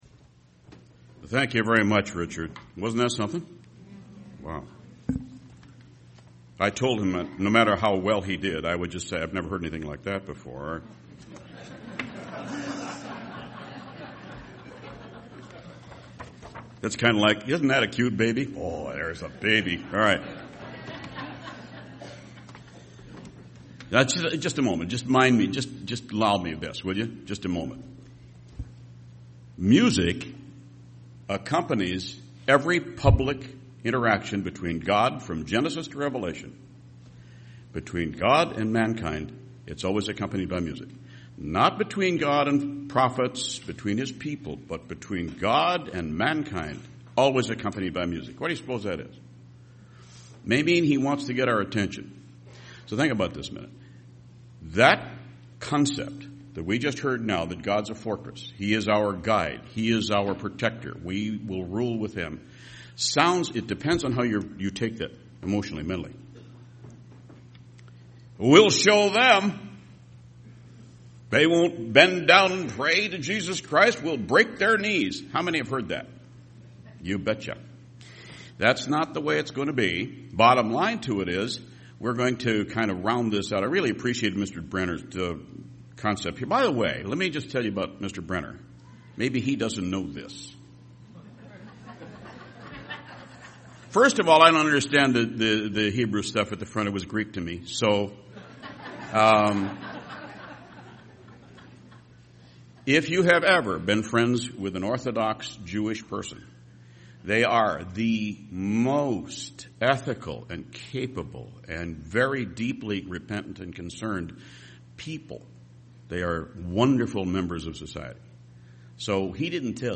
This sermon was given at the Cincinnati, Ohio 2018 Feast site.